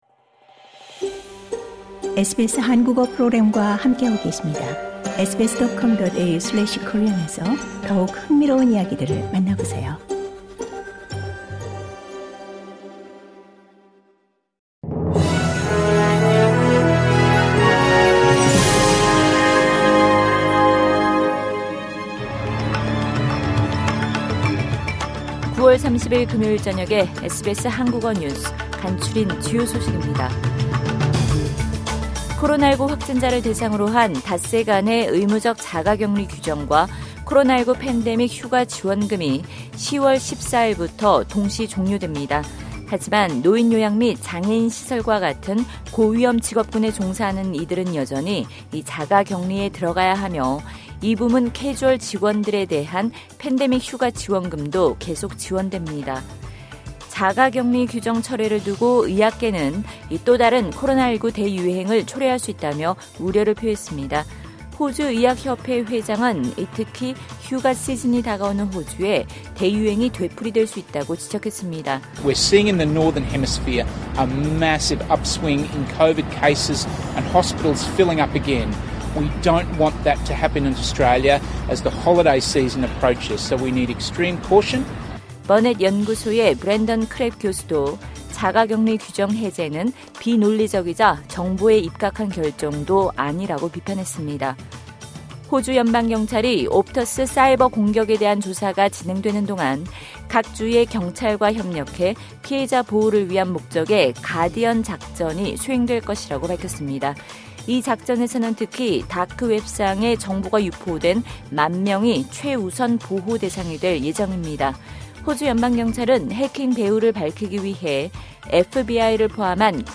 SBS 한국어 저녁 뉴스: 2022년 9월 30일 금요일